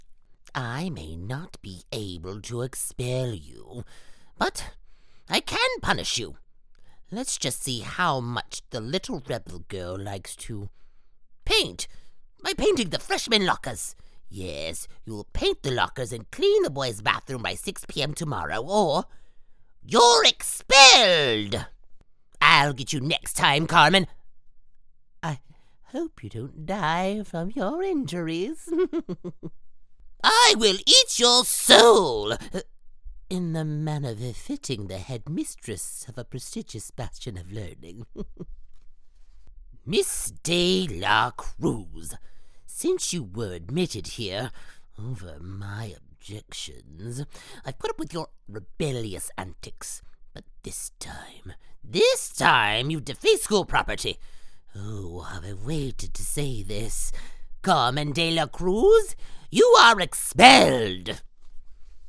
Gnarly Character .mp3